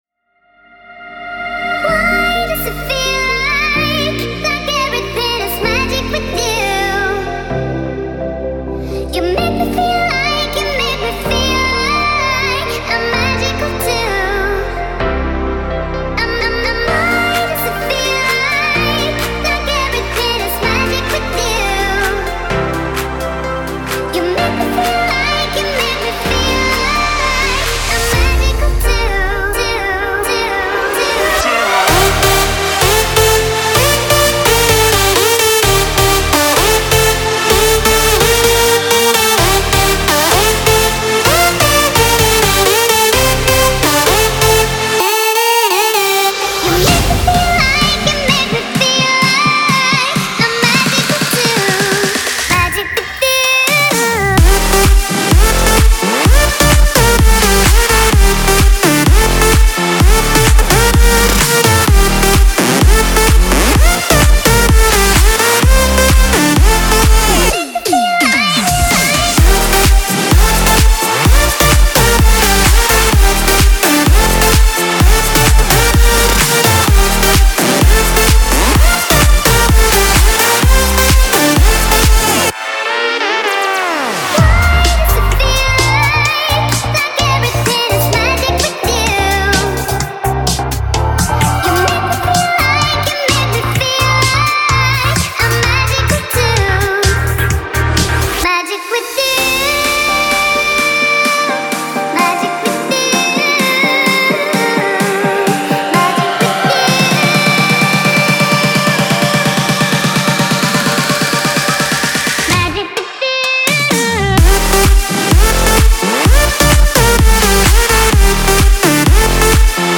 Genre: Electronic Dance